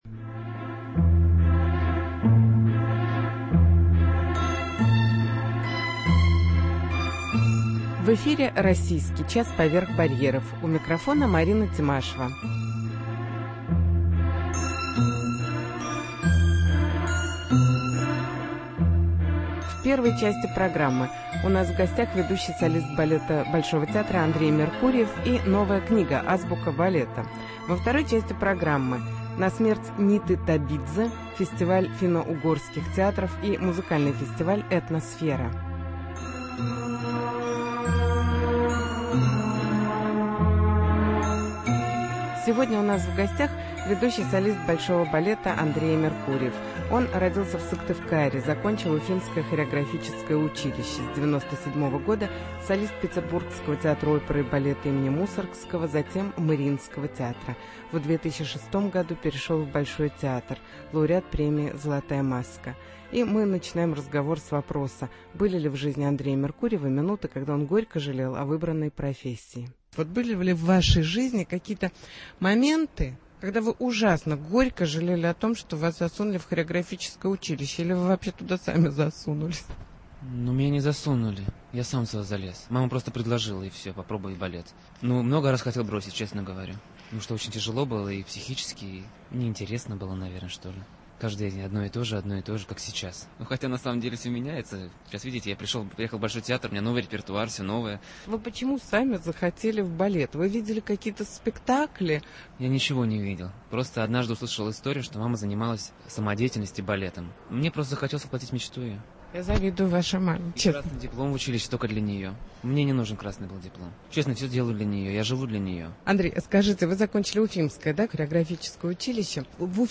Интервью с солистом балета Большого театра Андреем Меркурьевым